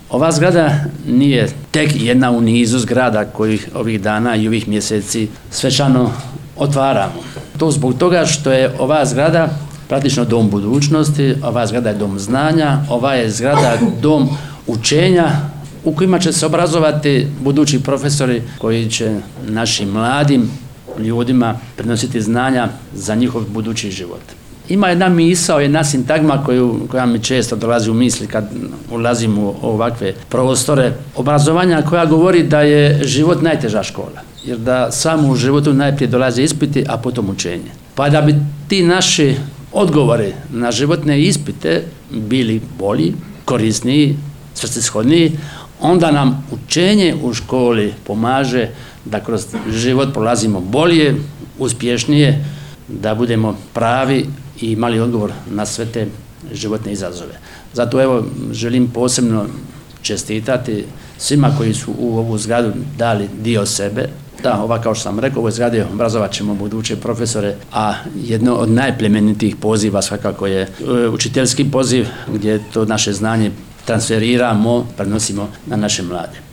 U utorak, 04. ožujka 2025. godine, u Petrinji je svečano otvorena novoobnovljena zgrada Učiteljskog fakulteta – Odsjek u Petrinji.
Potpredsjednik Vlade RH i ministar prostornoga uređenja, graditeljstva i državne imovine Branko Bačić